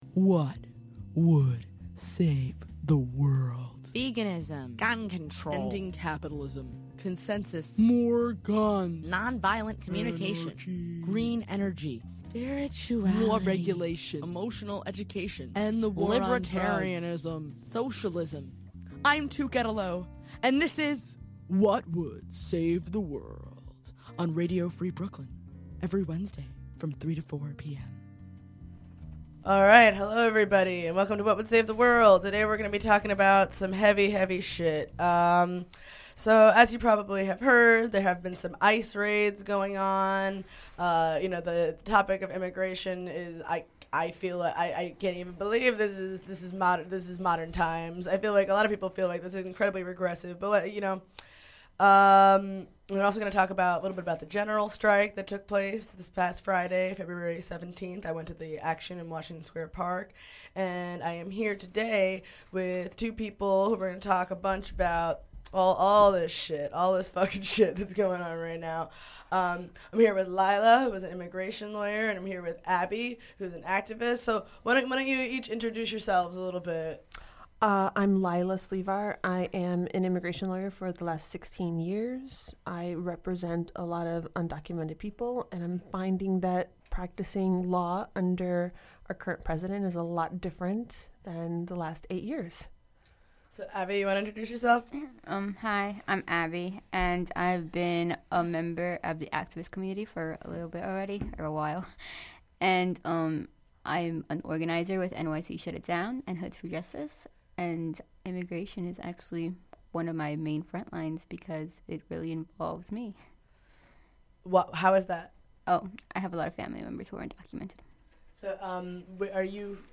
Intro music